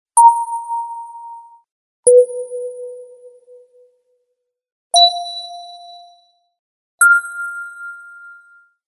ding0.mp3